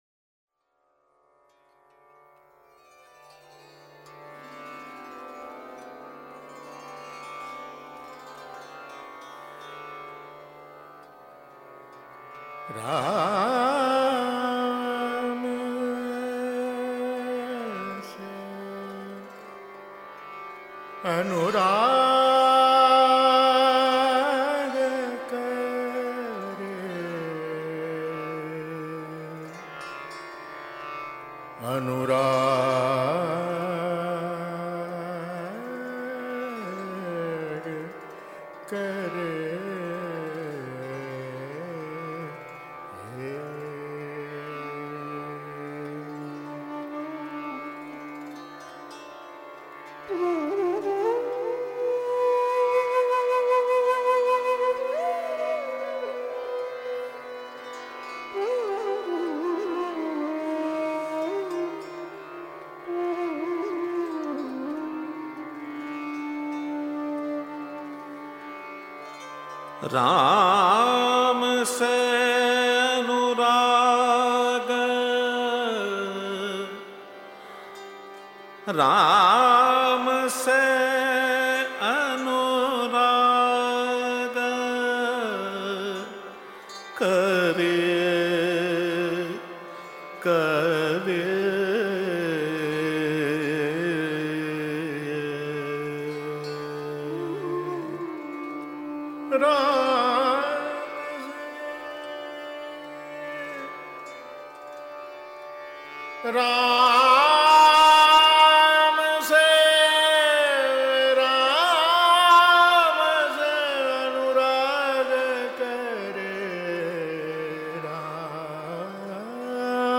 सुनिये इस कालजयी संगीत को और आत्मिक आनंद लीजिये…